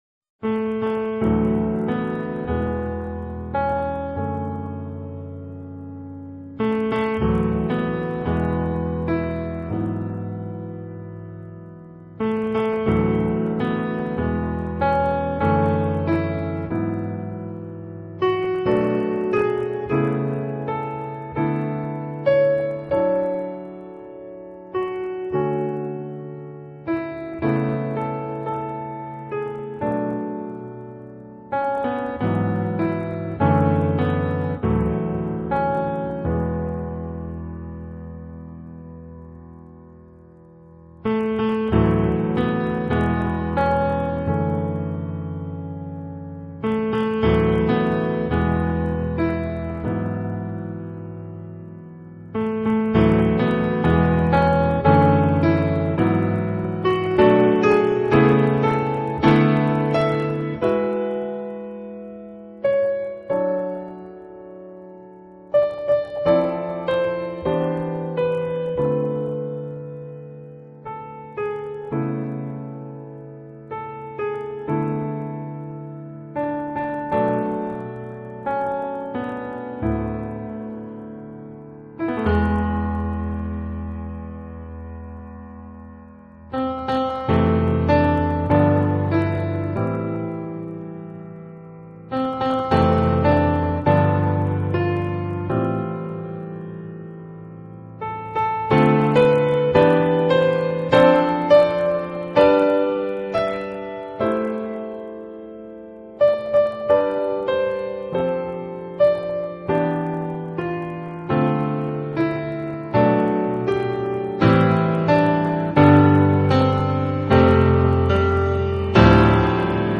器配置，使每首曲子都呈现出清新的自然气息。